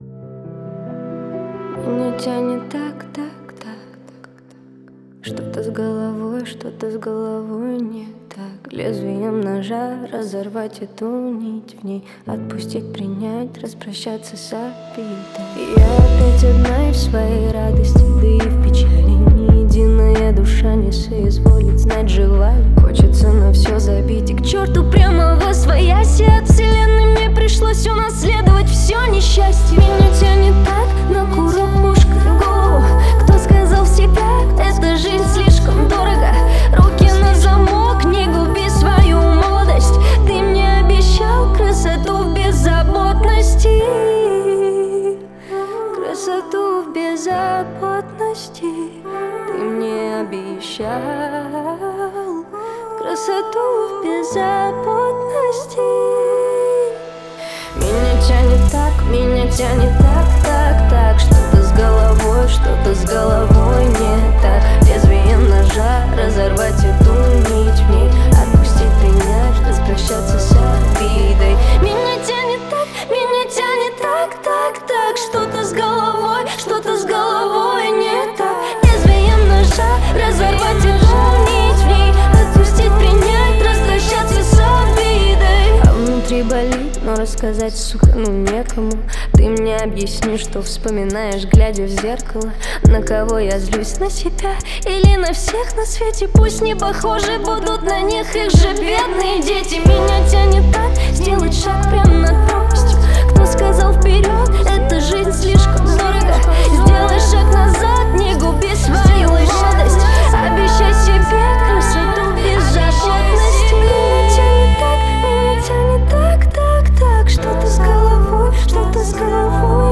звучит уверенно и эмоционально